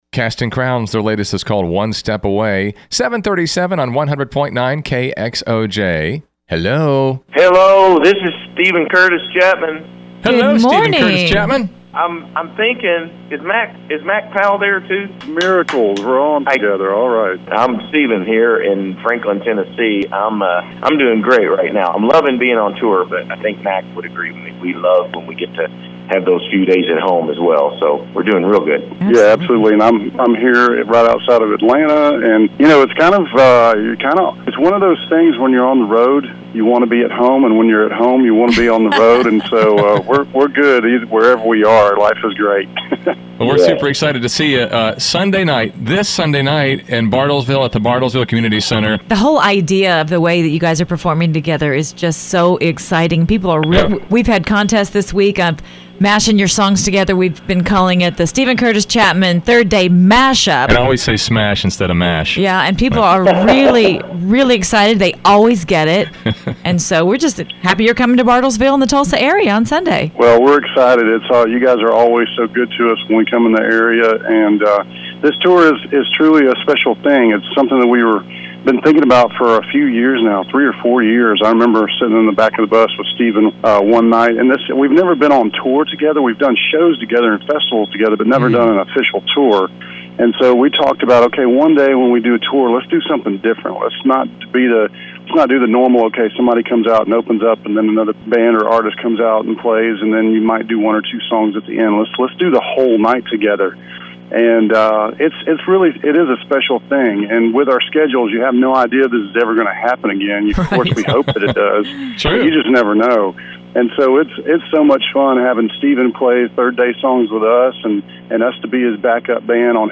SCC-AND-3D-INTERVIEW-for-blog.mp3